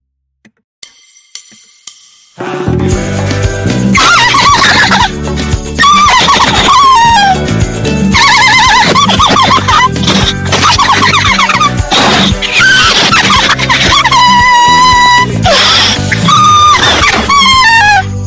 Birthday Giggles